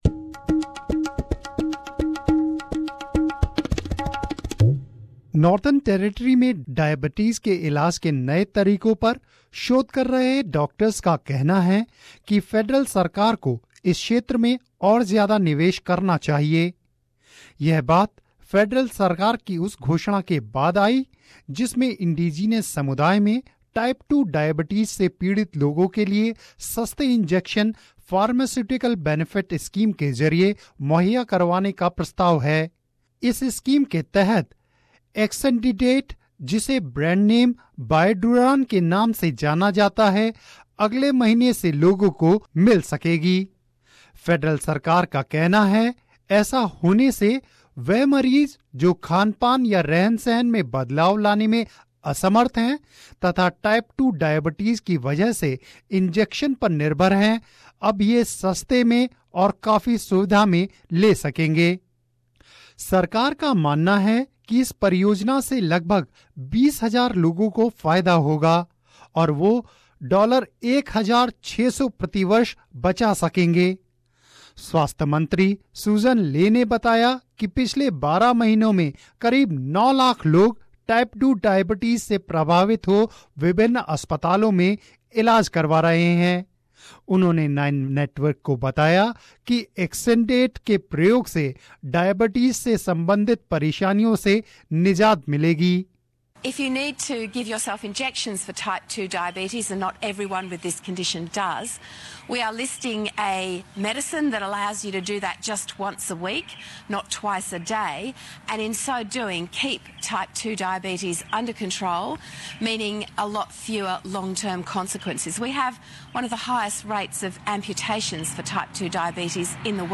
मेडिकल एक्सपर्ट्स का मनना है की एक नए डायबिटीज ट्रीटमेंट को फार्मास्यूटिकल बेनिफिट्स स्कीम में शामिल करने से इंडीजिनॉस और माइग्रेंट कम्युनिटीज को फायदा होगा...रिपोर्ट पेश कर रहे है